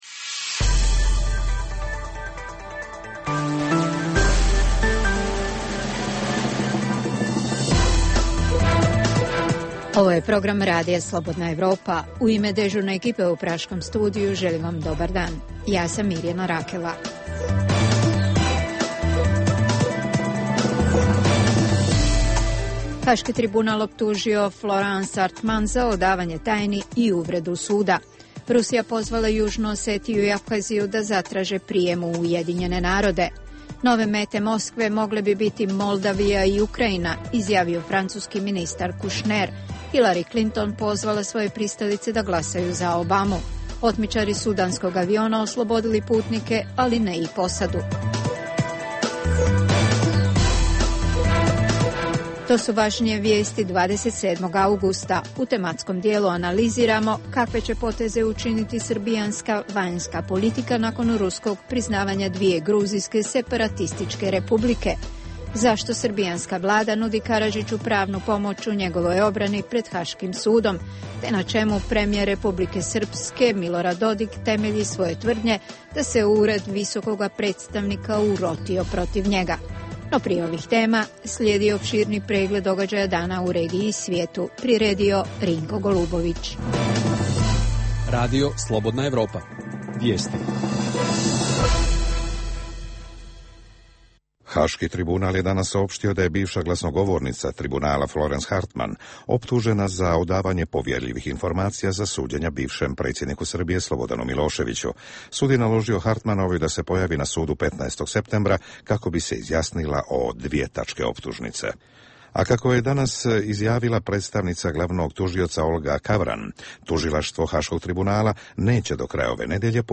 Između ostaloga analiziramo kakve će poteze učiniti srbijanska vanjska politika nakon ruskog priznanja dvije gruzijske separatističke republike; zašto srbijanska vlada nudi Karadžiću pravnu pomoć u njegovoj obrani pred Haškim sudom, te na čemu premijer Republike Srpske Dodik temelji svoje tvrdnje da se Ured visokoga predstavnika urotio protiv njega. Objavljujemo interview